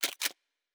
pgs/Assets/Audio/Sci-Fi Sounds/Weapons/Weapon 02 Reload 1.wav at master
Weapon 02 Reload 1.wav